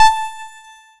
Mid pitch satisfying short notification sound
mid-pitch-satisfying-shor-2rqrzir2.wav